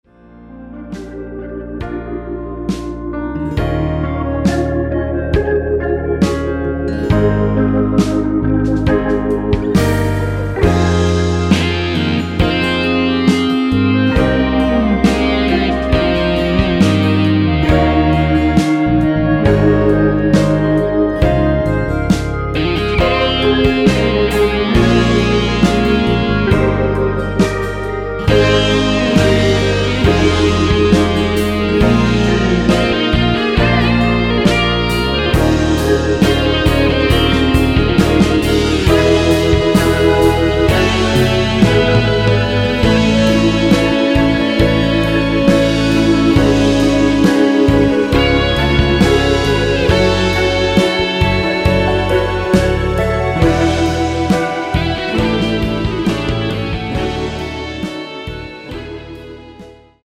원키에서(-7)내린 1절후 후렴으로 진행 되는 멜로디 포함된 MR입니다.(본문의 가사 부분 참조)
Ab
앞부분30초, 뒷부분30초씩 편집해서 올려 드리고 있습니다.